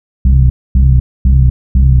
TSNRG2 Off Bass 013.wav